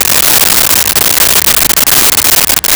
Car Engine Run 02
Car Engine Run 02.wav